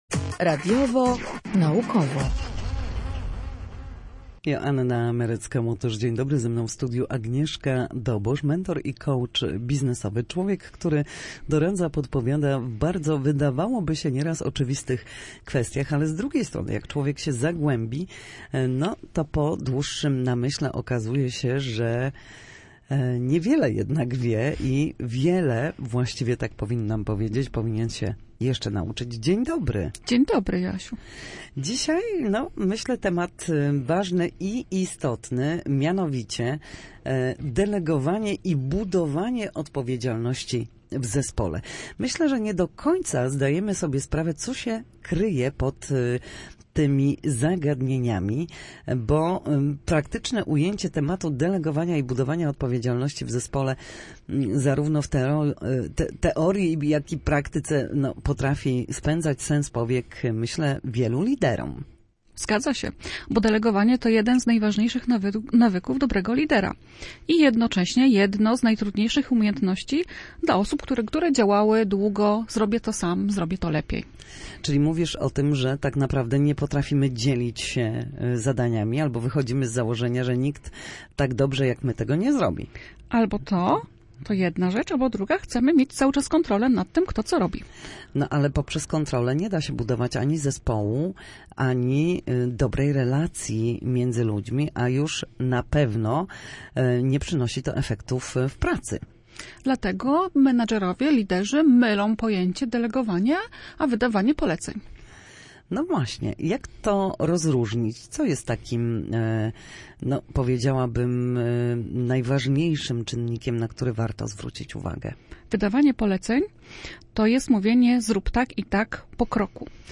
Na naszej antenie wyjaśniała, czym jest delegowanie zadań pracownikom, a także podpowiadała, jak budować odpowiedzialność w zespole.